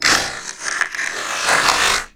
ALIEN_Communication_10_mono.wav